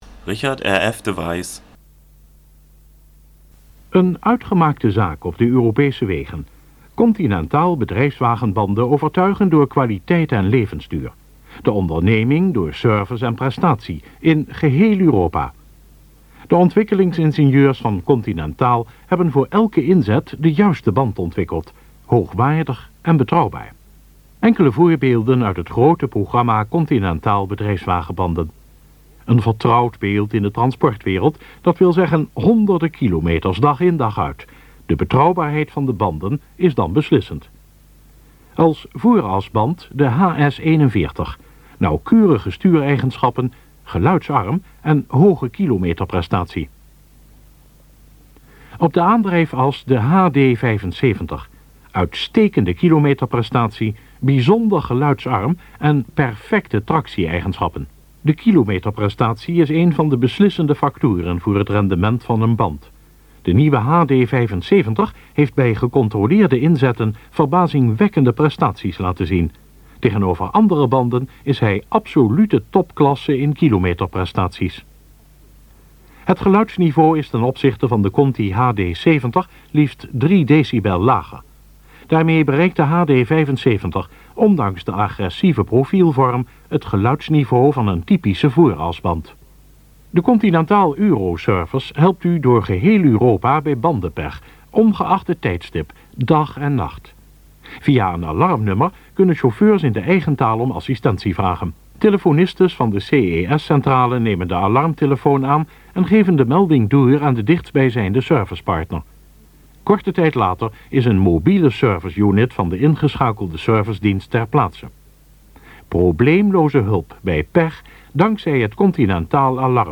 Mittel plus (35-65)